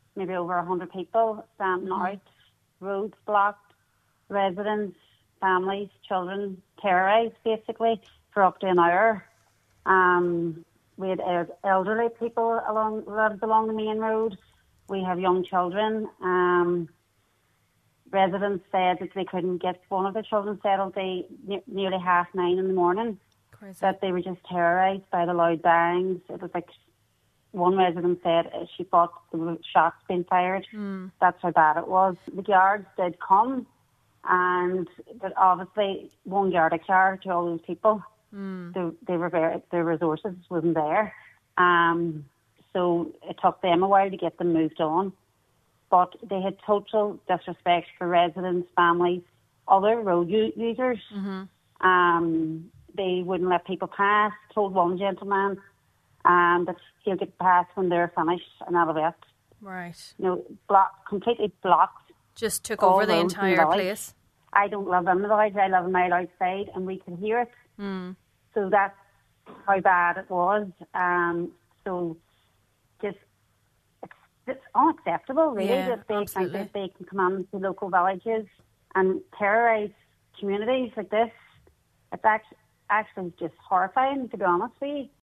on this morning’s Nine til Noon Show